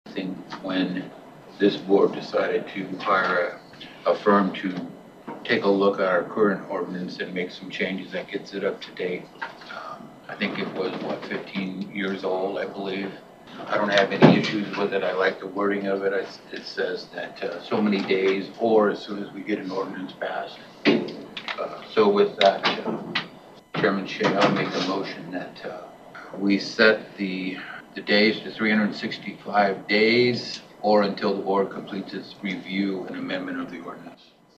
Supervisor Tim Wichman is in favor of the temporary moratorium…